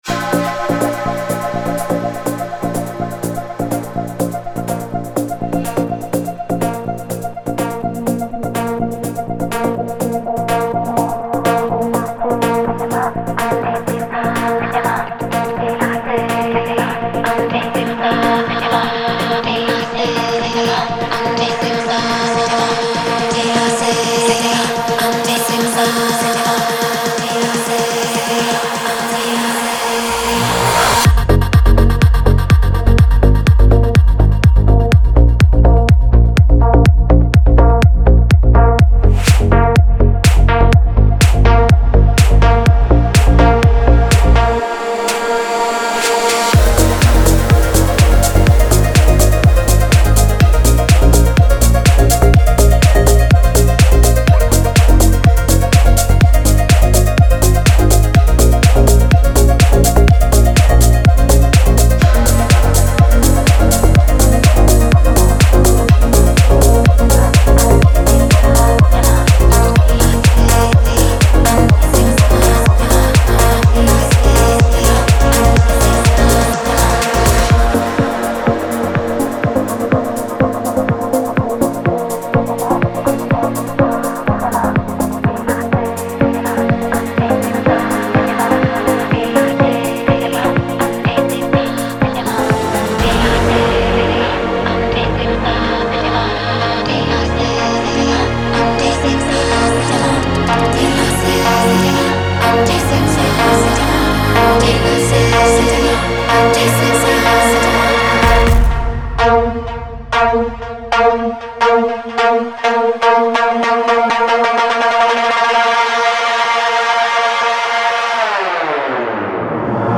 • Жанр: Techno, House